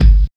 100 KICK 4.wav